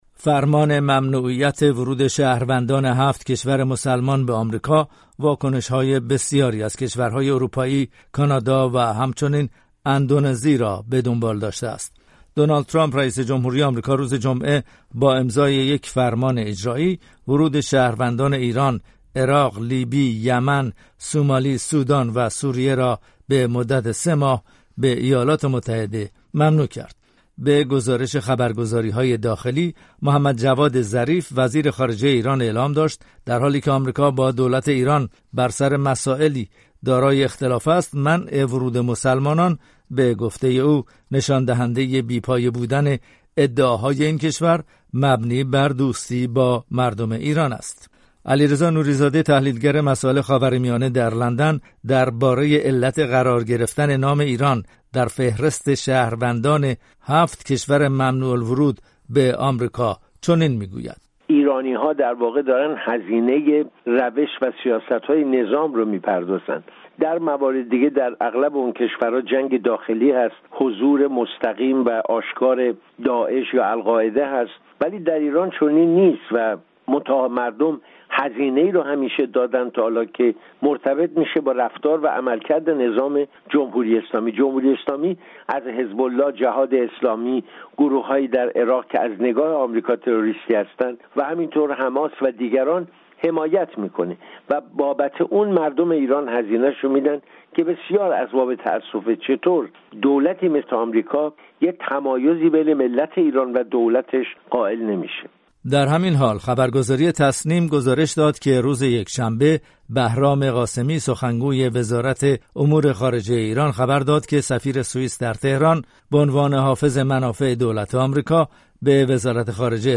گزارش‌های رادیویی
رادیو فردا در گزارش خود همچنین احتمال پیوستن کشورهای اسلامی در خاورمیانه را برای ممنوعیت ورود آمریکائیان و مقابله به مثل با دو کارشناس درمیان گذاشته است.